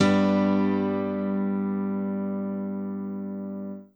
BAL Piano Chord 2 G.wav